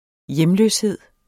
Udtale [ ˈjεmløsˌheðˀ ]